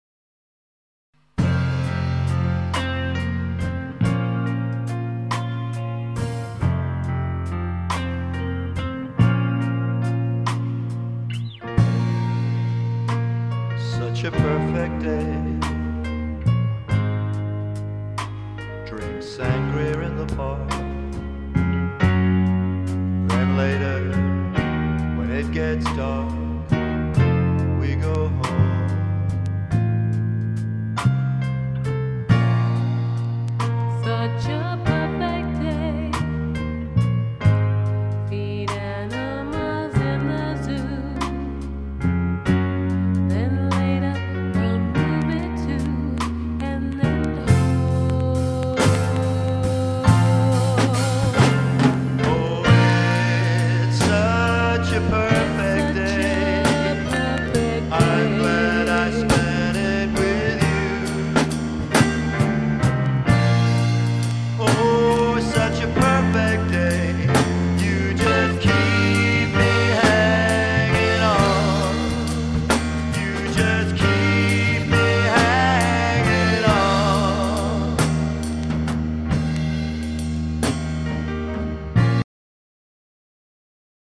POP/REGGAE